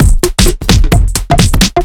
OTG_TripSwingMixD_130b.wav